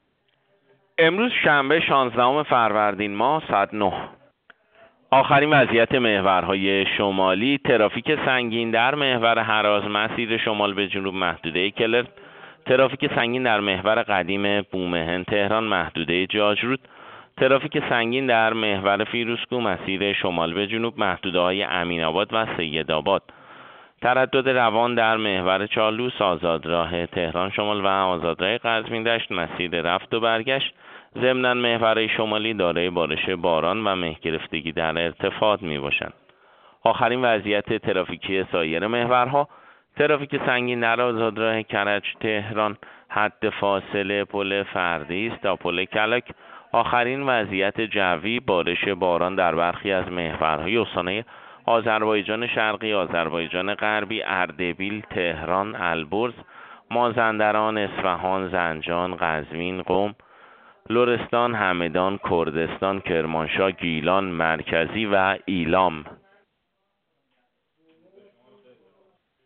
گزارش رادیو اینترنتی از آخرین وضعیت ترافیکی جاده‌ها ساعت ۹ شانزدهم فروردین؛